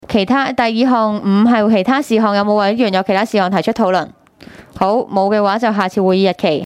工作小組會議的錄音記錄